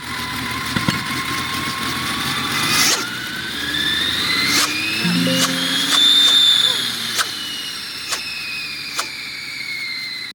But when I hear a loud whining, grinding, or siren-like noise, I know something is wrong.
turbo-sound.mp3